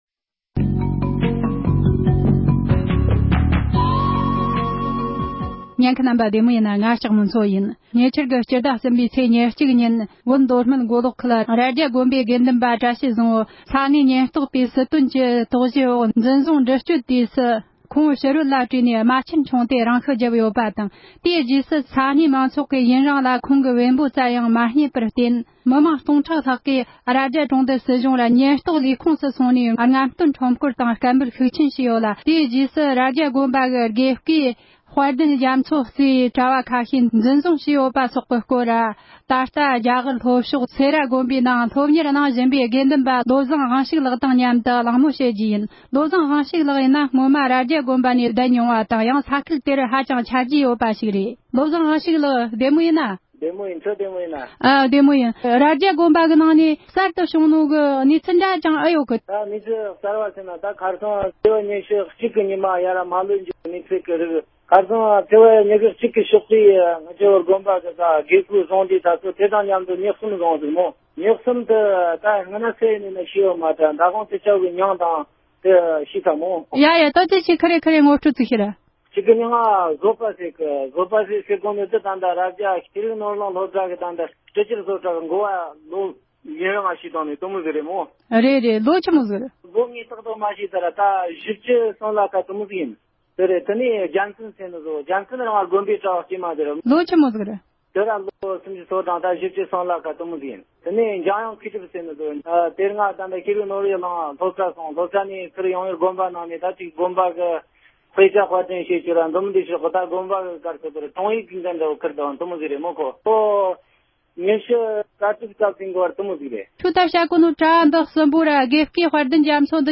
སྒྲ་ལྡན་གསར་འགྱུར། སྒྲ་ཕབ་ལེན།
གླེང་མོལ